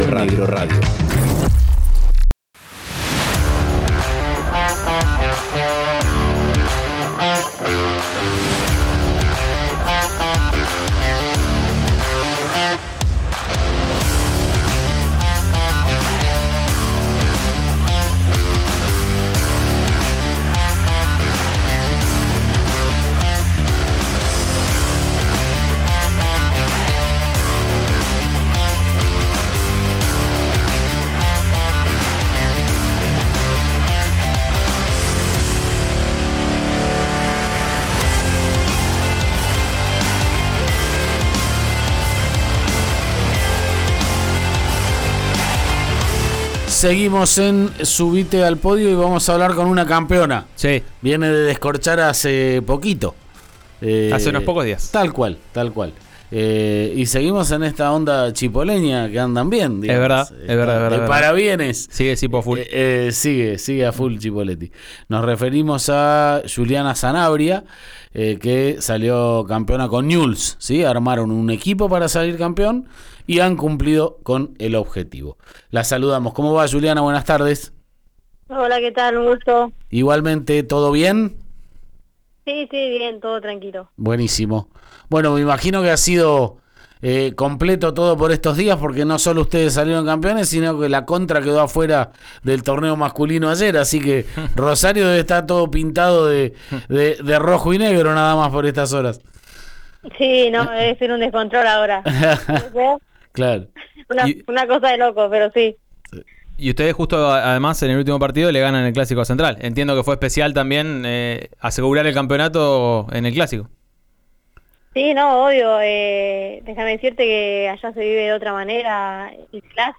en diálogo con «Subite al Podio» de Río Negro Radio.